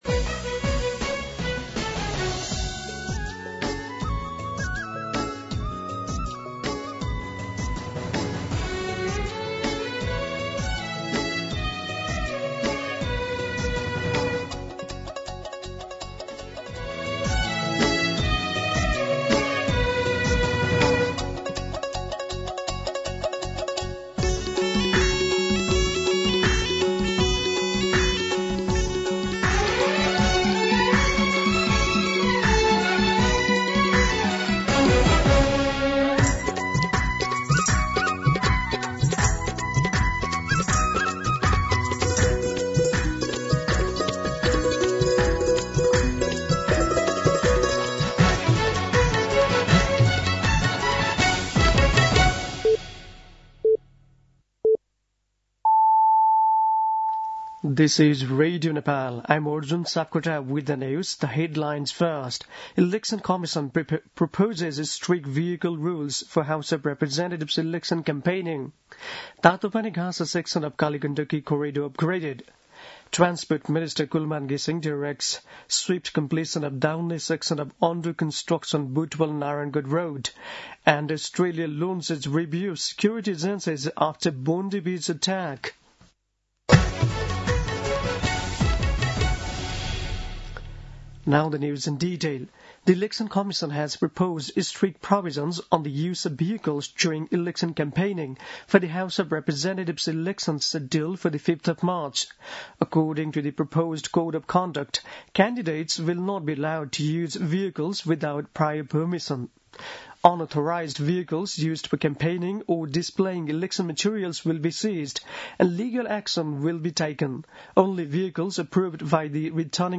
दिउँसो २ बजेको अङ्ग्रेजी समाचार : ६ पुष , २०८२